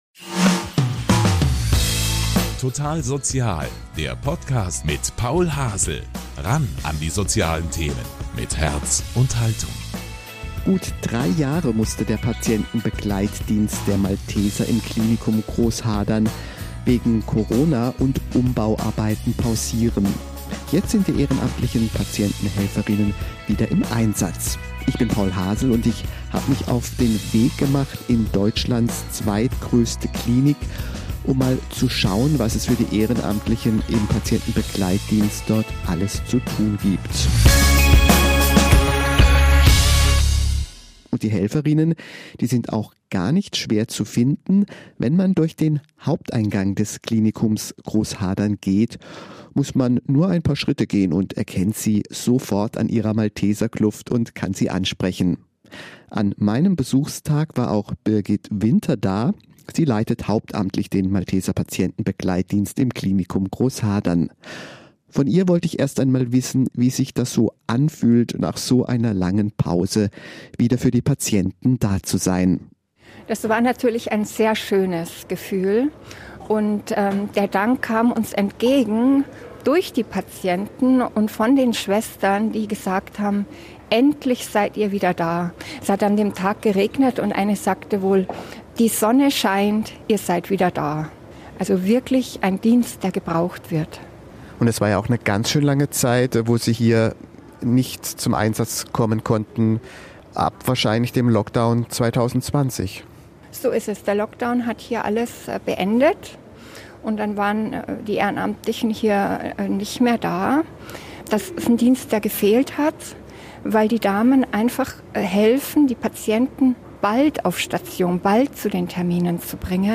Vor Ort beim Malteser Patienten-Begleitdienst im Klinikum Großhadern ~ Total Sozial! Podcast
Wir haben die Patienten-Helferinnen bei der Arbeit besucht